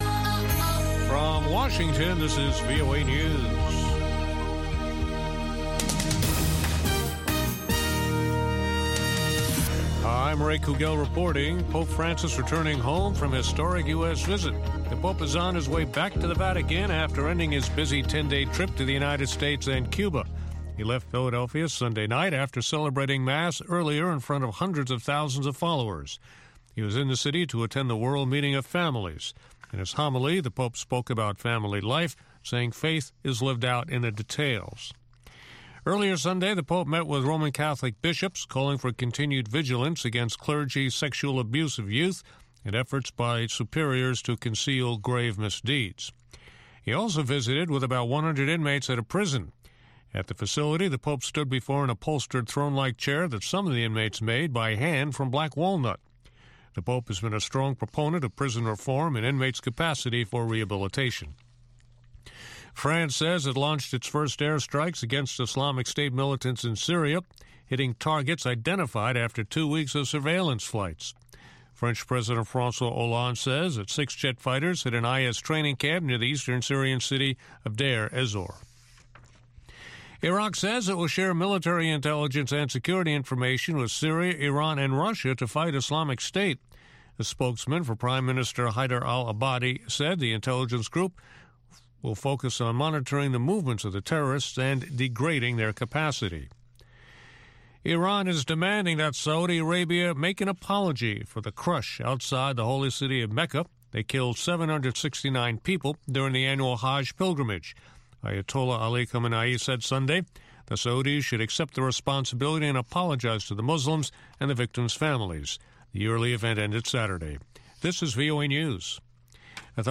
from N’dombolo to Benga to African Hip Hop